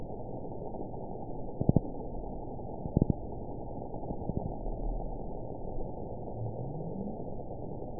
event 922380 date 12/30/24 time 05:27:36 GMT (11 months ago) score 9.56 location TSS-AB04 detected by nrw target species NRW annotations +NRW Spectrogram: Frequency (kHz) vs. Time (s) audio not available .wav